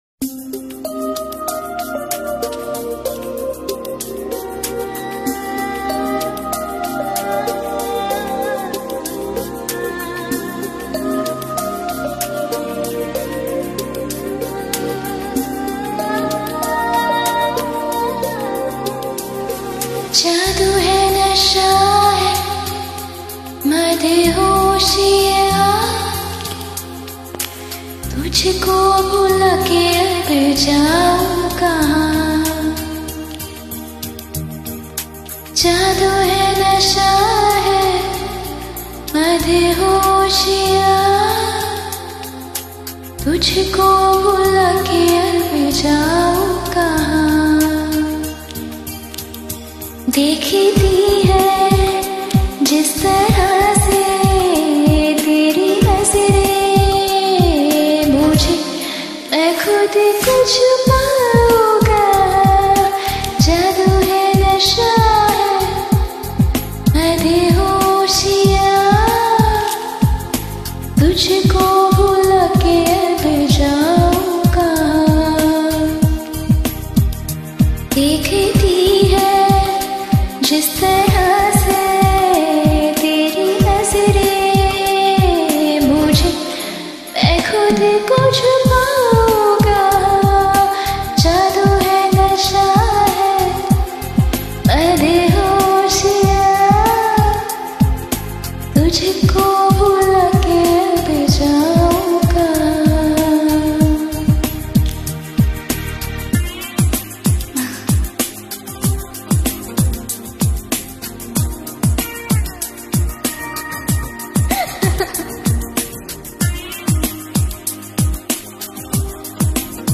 A cover of the song